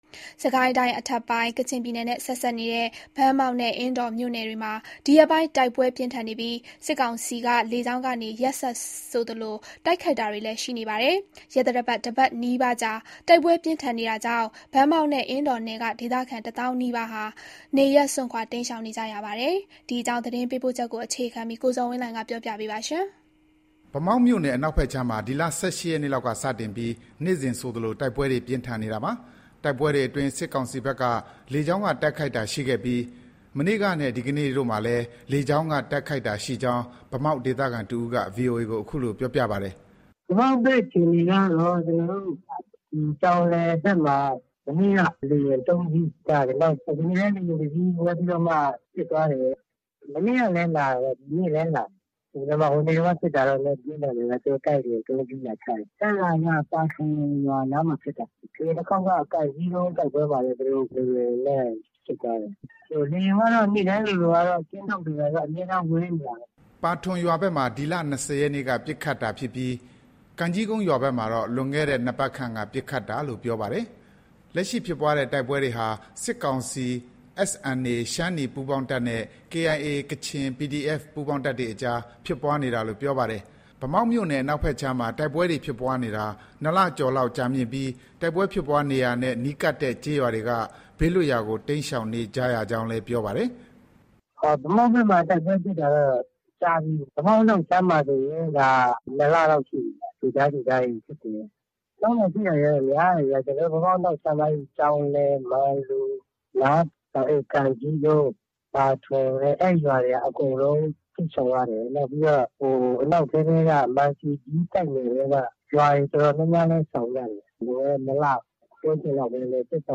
ဒီတိုက်ပွဲတွေအတွင်း စစ်ကောင်စီဘက်က လေကြောင်းက တိုက်ခိုက်တာရှိခဲ့ပြီး မနေ့ကနဲ့ ဒီကနေ့တို့မှာလည်း လေကြောင်းက တိုက်ခိုက်တာရှိကြောင်း ဗန်းမောက် ဒေသခံတစ်ဦးက VOA ကို အခုလို ပြောပါတယ်။
လက်ရှိ ဖြစ်ပွားနေတဲ့ တိုက်ပွဲအခြေအနေကို အင်းတော် PDF တွေနဲ့ နီးစပ်သူတစ်ဦးက အခုလို ပြောပါတယ်။